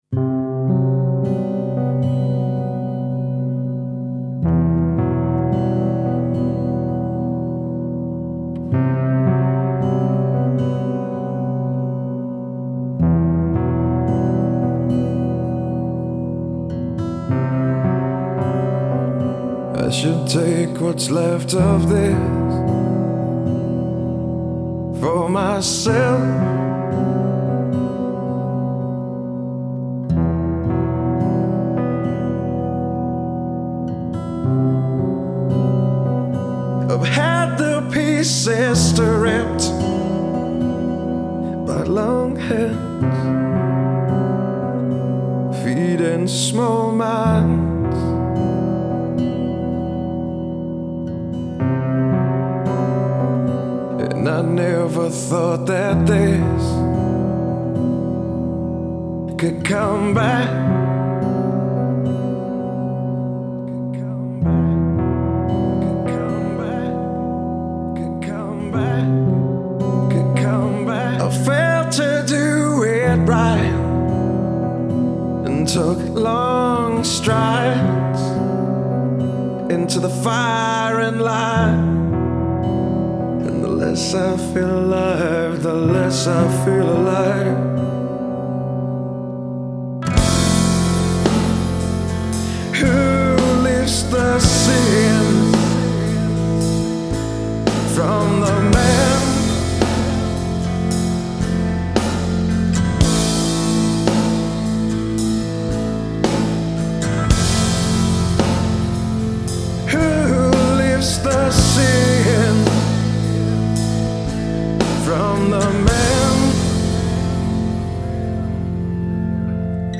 progressive rock Art Rock